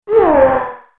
But the sound he makes when he's defeated is very different: